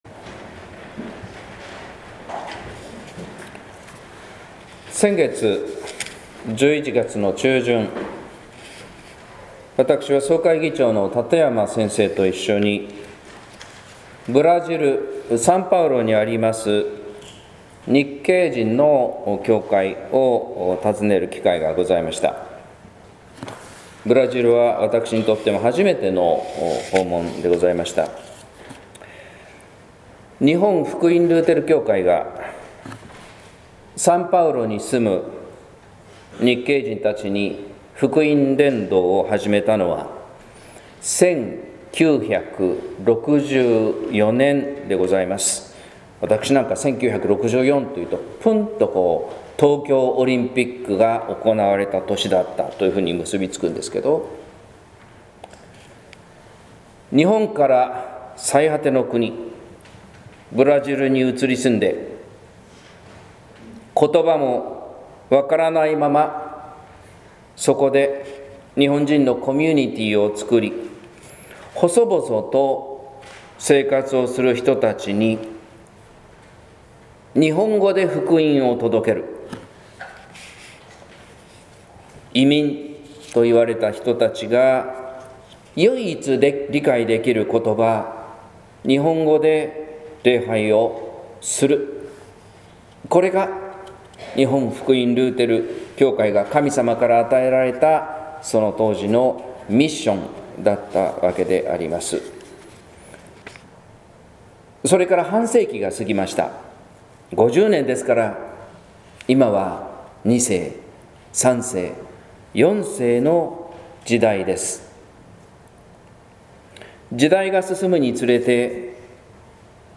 説教「インマヌエルの神秘」（音声版）